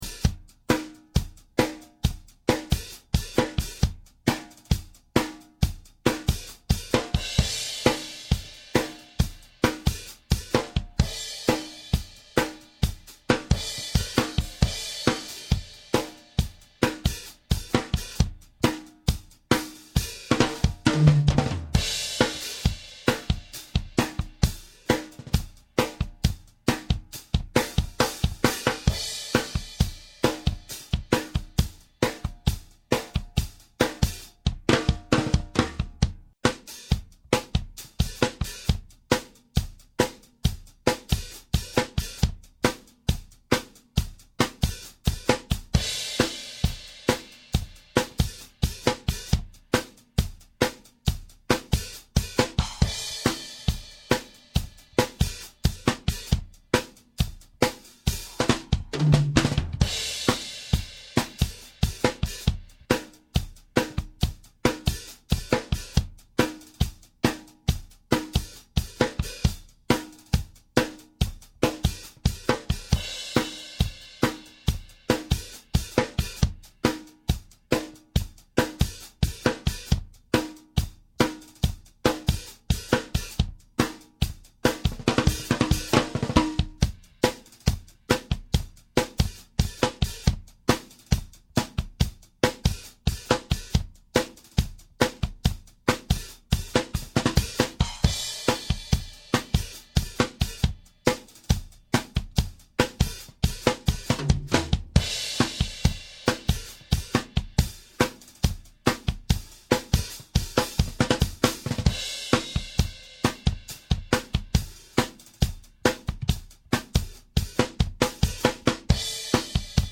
Cocktail Drum,
Different Snare Sounds
Here are 2 mp3's with 4 different snare sound caused by remote snare off, on, with Moon Gel also off or on.
These tracks were recorded without any compression, gating or effects.
0:00-0:36 Moon Gel Off, Remote Snare Off
0:36-1:02 Moon Gel Off, Remote Snare On
1:02-1:26 Moon Gel On, Remote Snare Off
1:26-2:13 Moon Gel On, Remote Snare On
Sticks.mp3